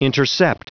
Prononciation du mot intercept en anglais (fichier audio)
Prononciation du mot : intercept